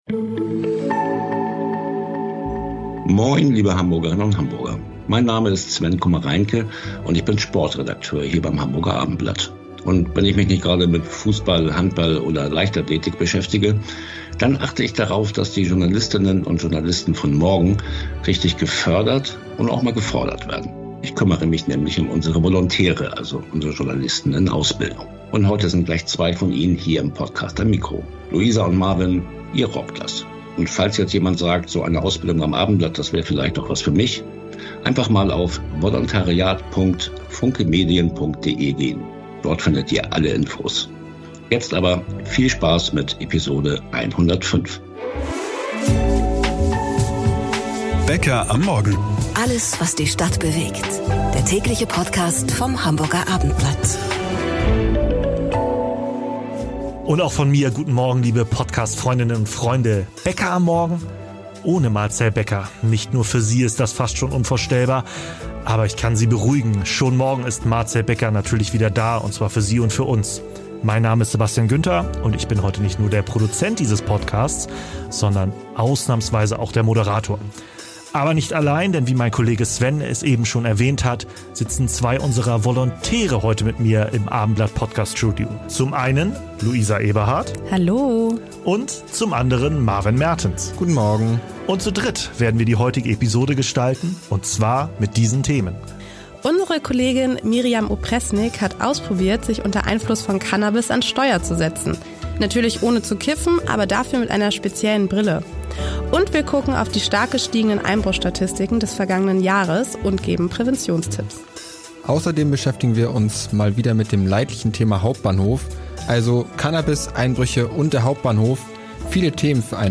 Dafür mit den Journalistinnen und Journalisten von Morgen: Unseren VolontärInnen - Die Themen: Cannabis, Einbrüche und der Hauptbahnhof